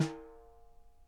Drum Samples
S n a r e s